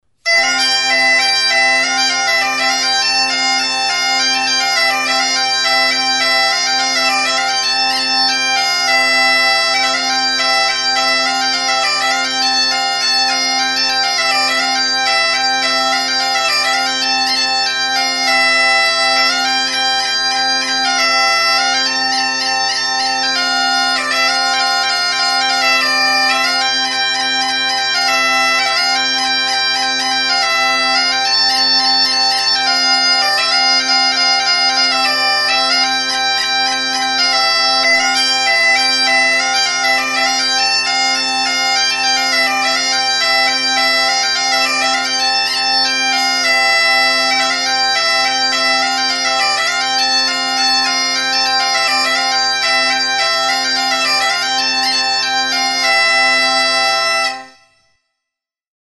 GAITA | Soinuenea Herri Musikaren Txokoa
Enregistr� avec cet instrument de musique.